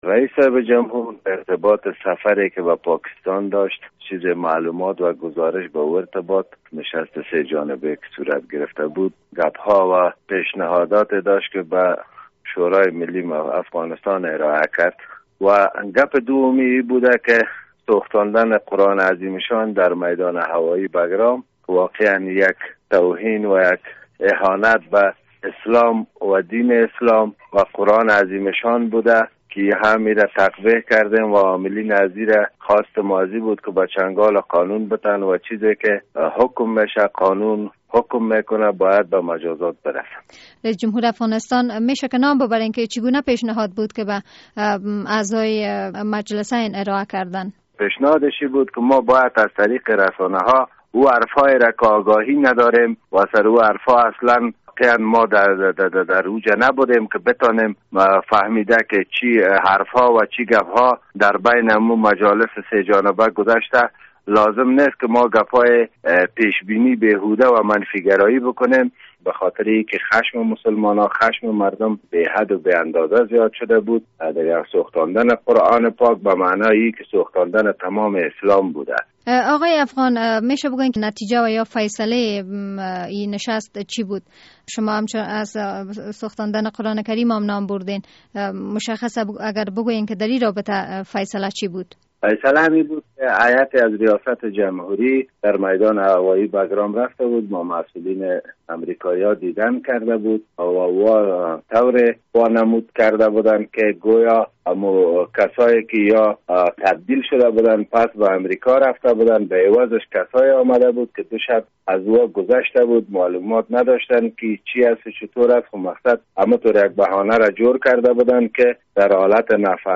مصاحبه در مورد دیدار هیات ولسی جرگه و مشرانوجرگه با حامد کرزی